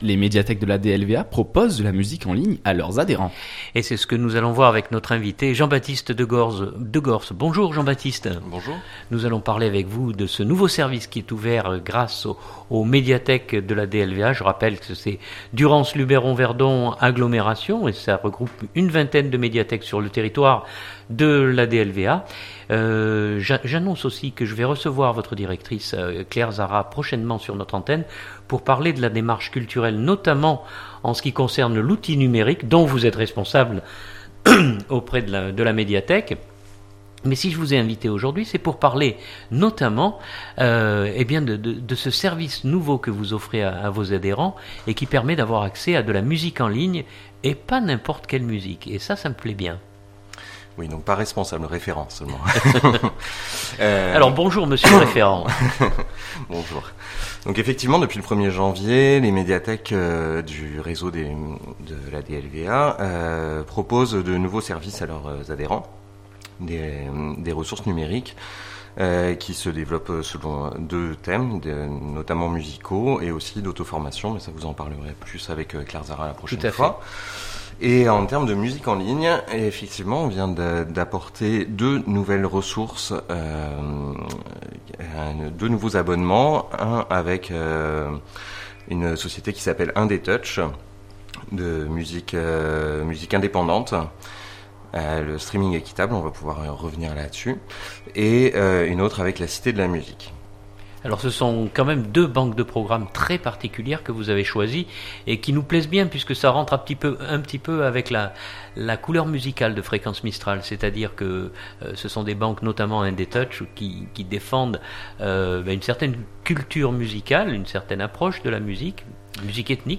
Journal du 2017-01-18